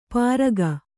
♪ pāraga